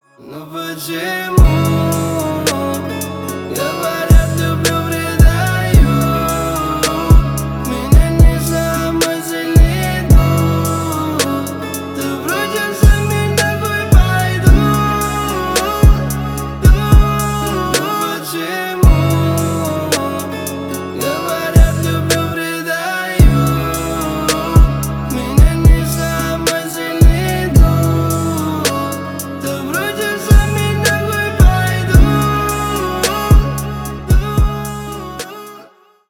Рэп и Хип Хоп
спокойные # грустные